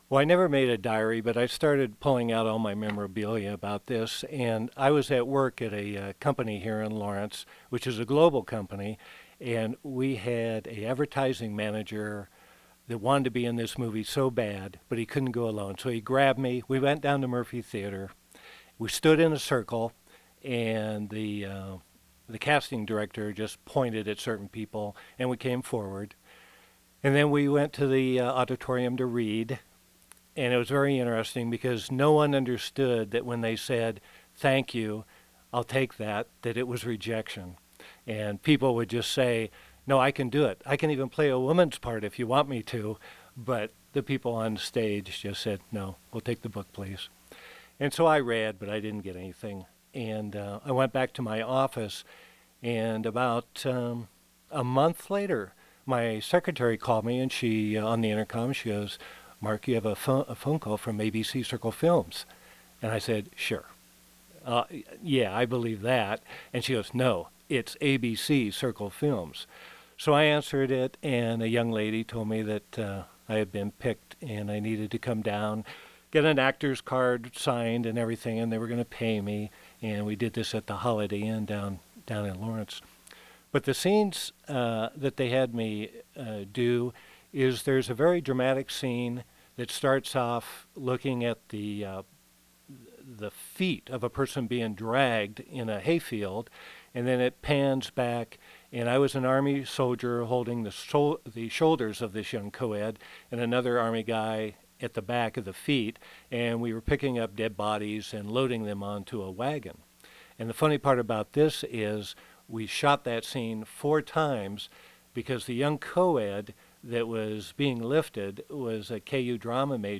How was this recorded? The interview was conducted at the Watkins Museum of History on June 28, 2012.